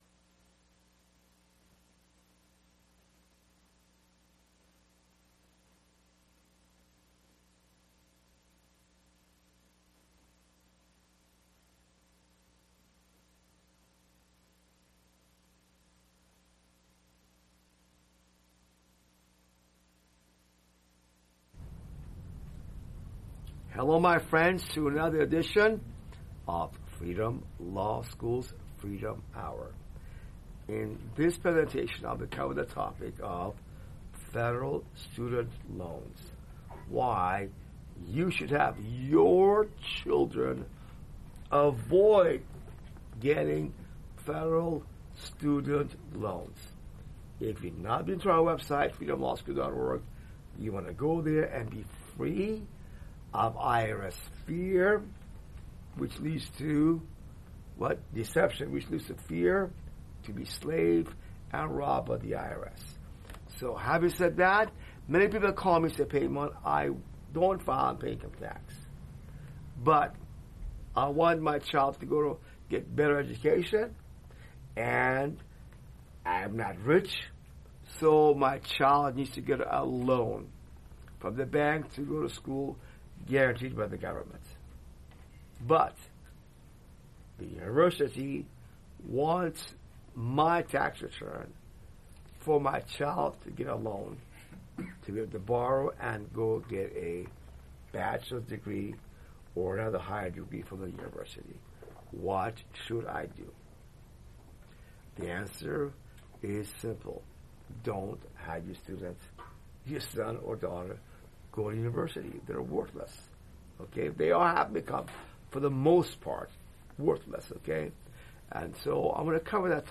This FreedomLawSchool talk suggests skipping federal student loans because many degrees don't lead to good jobs. Instead, focus on learning skills like creativity and problem-solving, which are more valuable today. The speaker also warns that the loan system mainly helps banks and the government, leaving students with heavy debt.